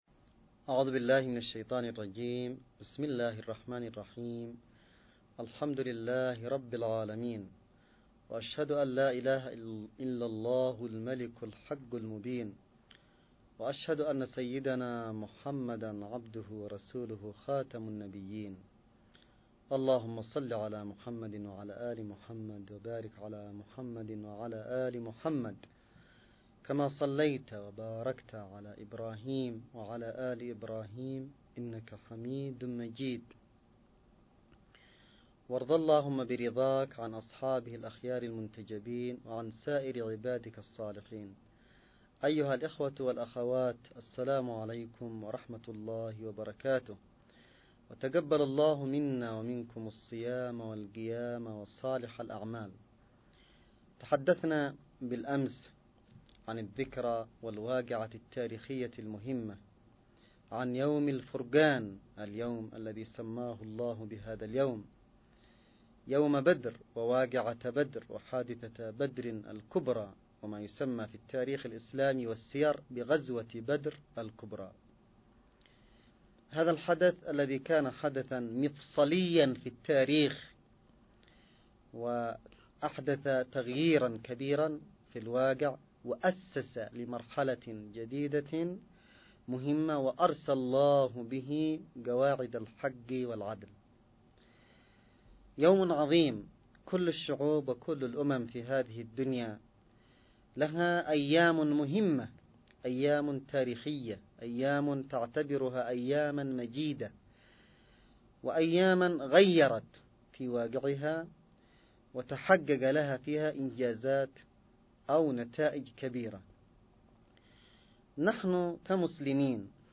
نص+أستماع لمحاضرة الرمضانية الحادية عشر للسيد عبدالملك الحوثي بعنوان غزوة بدر الكبرى 02
المحاضرة_الرمضانية_الحادية_عشر_للسيد.mp3